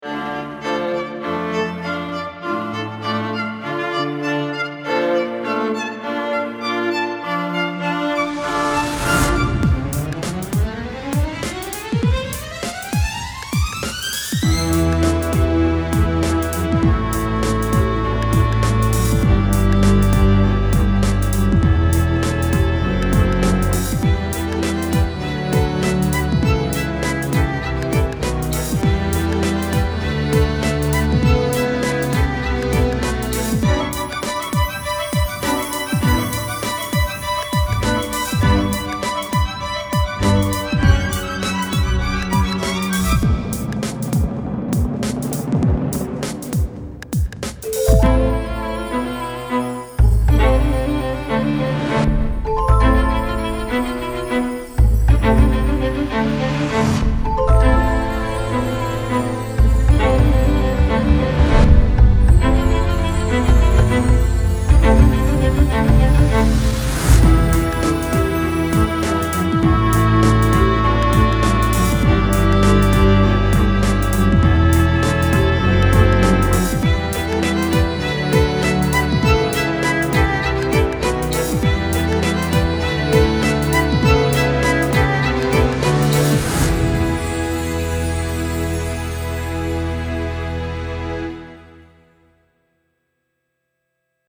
Classical Pop